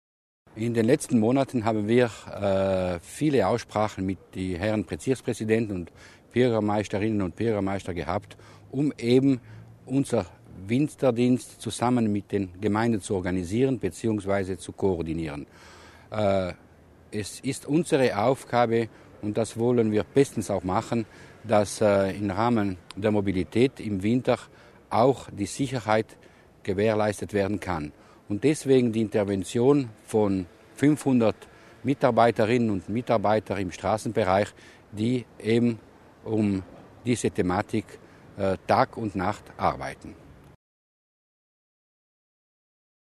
Landesrat Mussner zum Einsatz des Straßendienstes